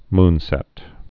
(mnsĕt)